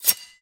AWP_Dagger_UnSheath.wav